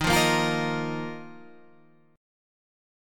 D# Suspended 2nd